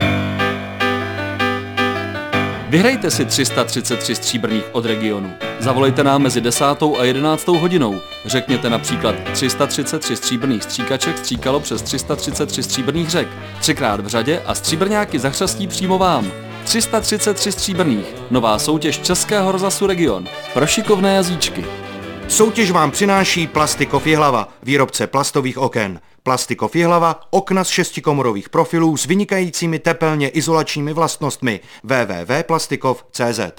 jazykolamy_plastikov.mp3